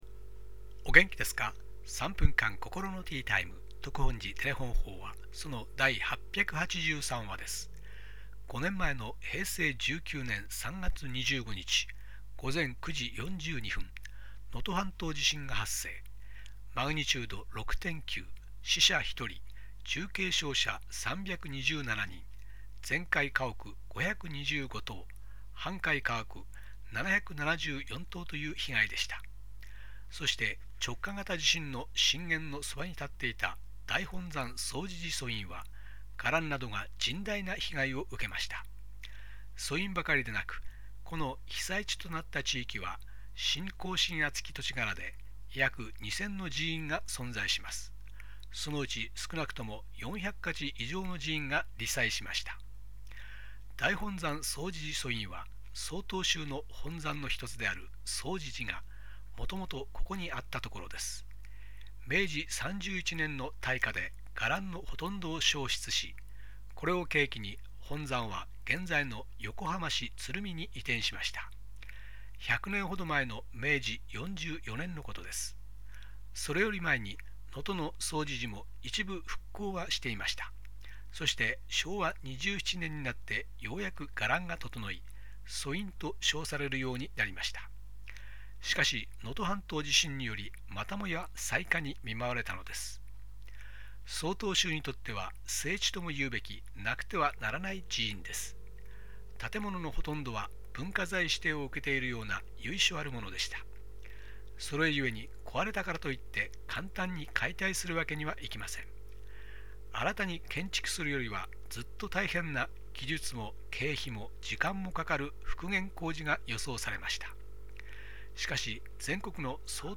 テレホン法話